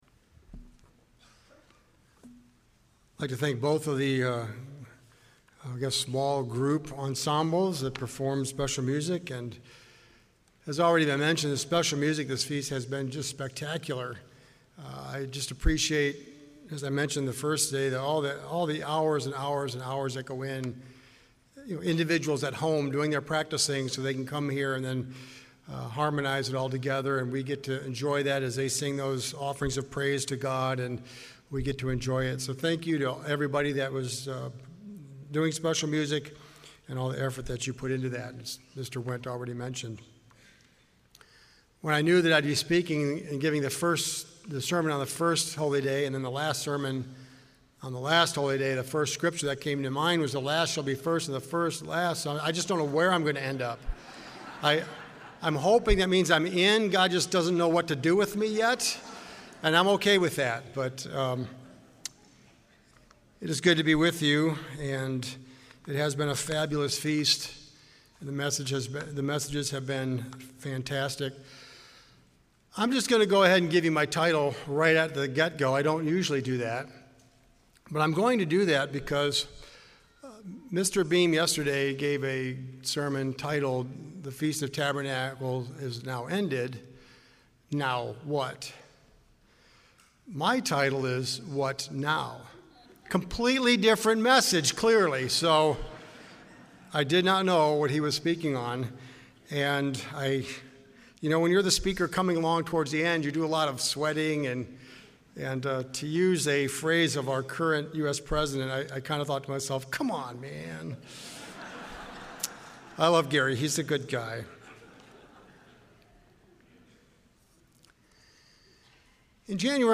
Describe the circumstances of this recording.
This sermon was given at the Jekyll Island, Georgia 2022 Feast site.